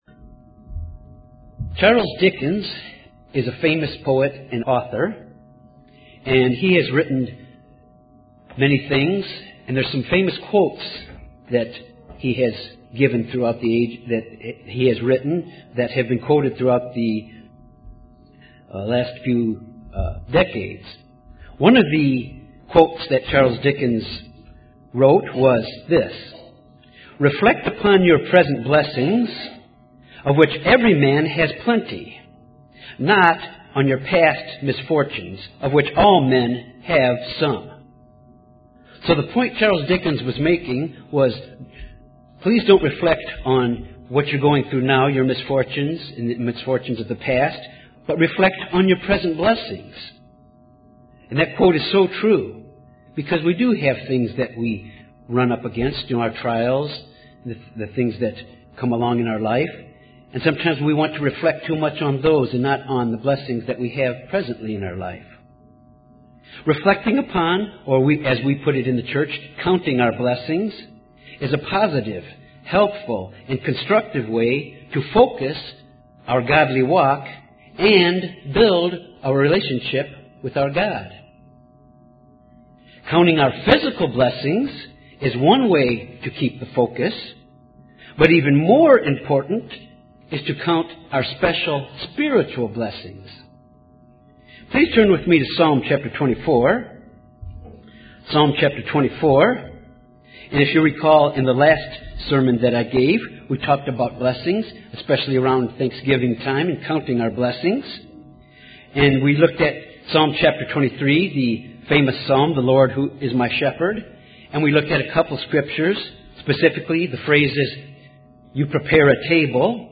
Given in Little Rock, AR Jonesboro, AR
UCG Sermon Studying the bible?